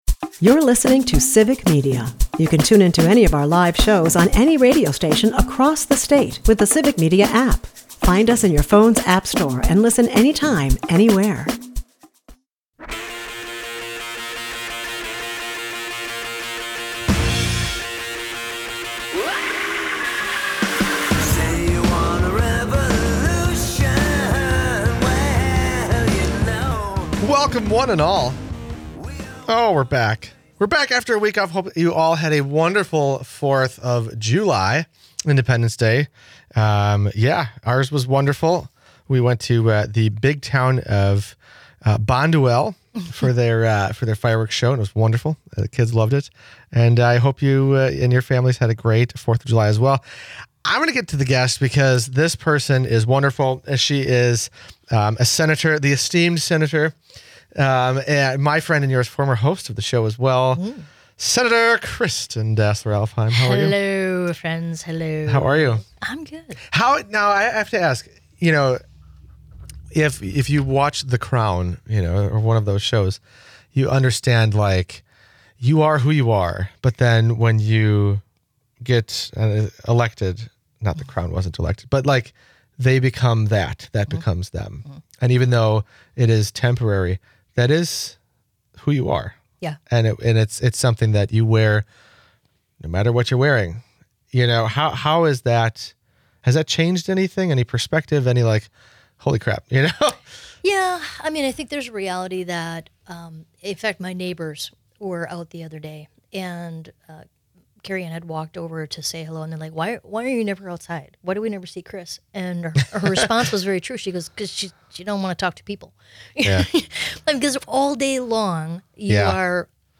This week, State Senator Kristin Dassler-Alfheim joins the show to talk about the state budget!